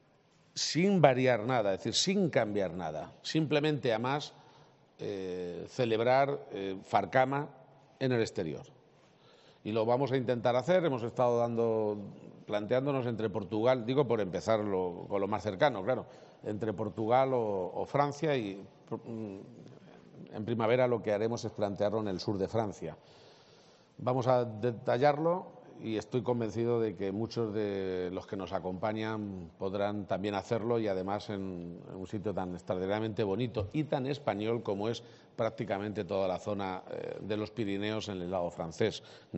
>> Así lo ha anunciado el presidente de Castilla-La Mancha en la inauguración de la XLI edición de la Feria Regional de Artesanía